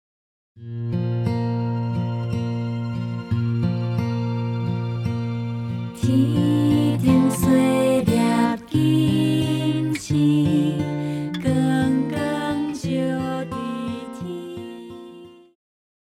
Christian
Pop chorus,Children Voice
Band
Hymn,POP,Christian Music
Voice with accompaniment